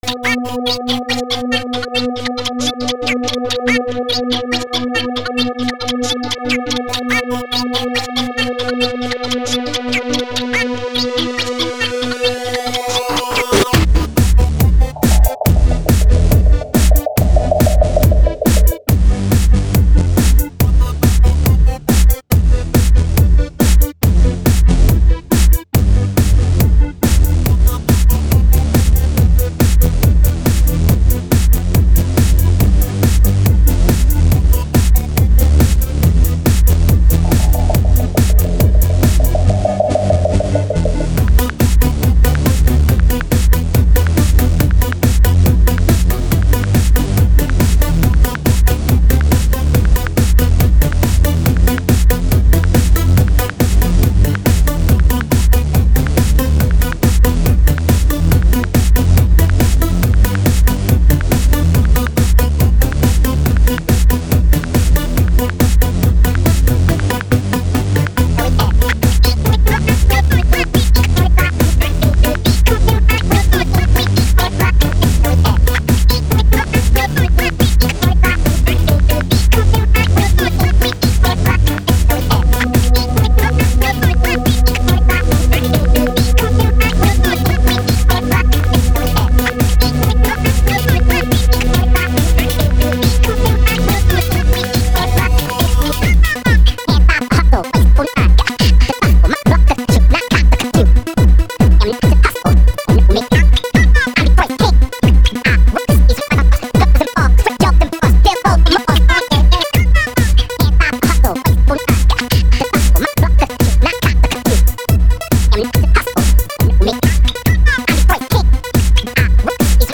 Клубные треки